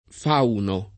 fauno [ f # uno ]